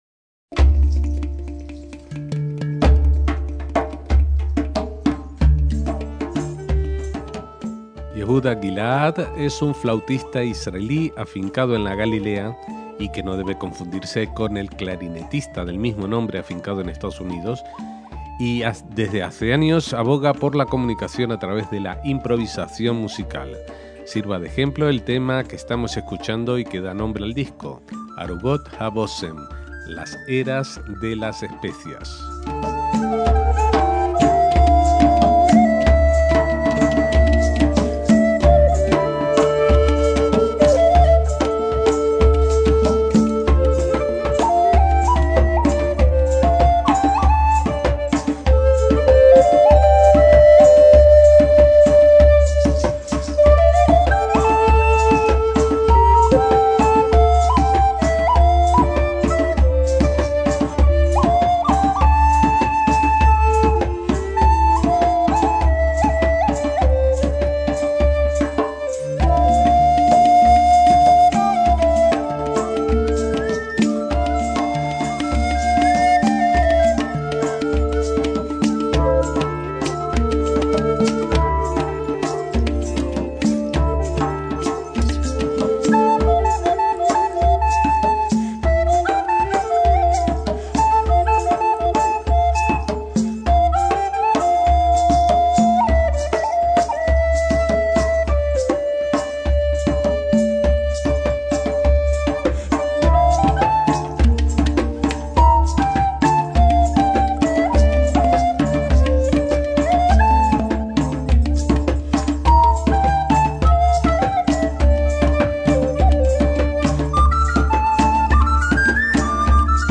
flautista
percusionista